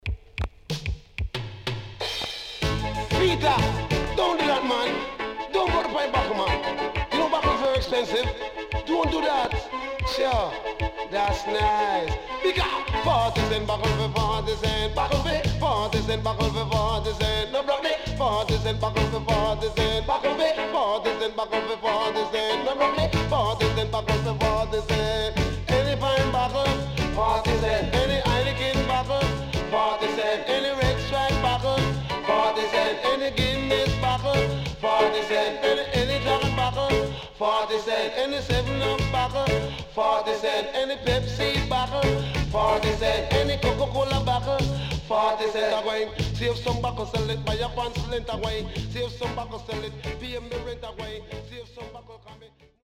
HOME > LP [DANCEHALL]
SIDE A:少しノイズ入りますが良好です。